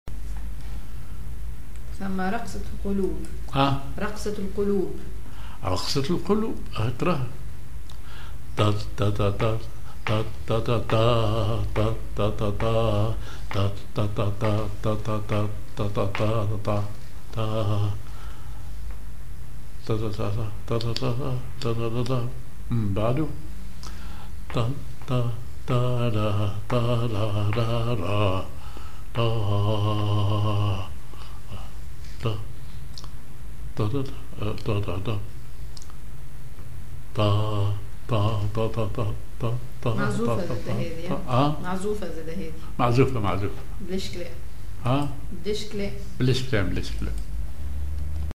عجم على درجة الراست (دو كبير)
سير ذو نفس عسكري
genre أغنية